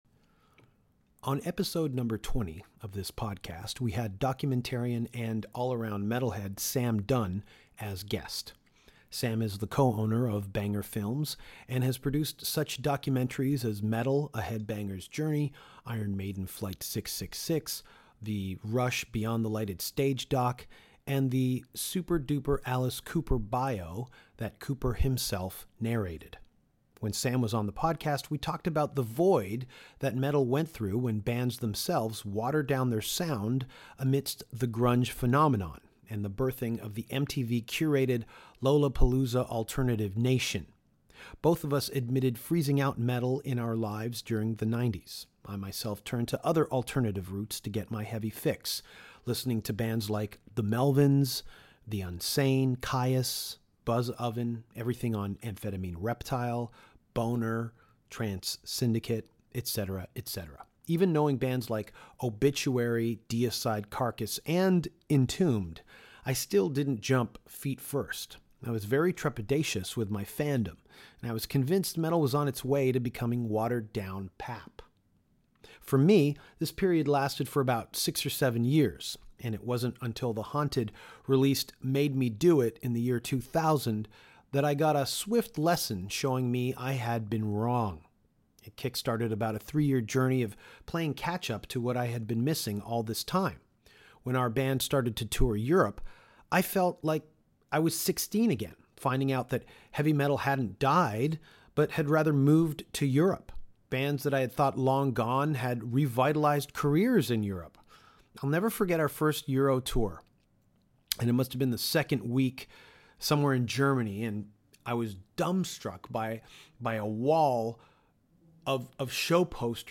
Entombed A.D. jumped on the podcast to talk to Danko during their Toronto stop back in April 2016, while on tour with Amon Amarth and Exmortus.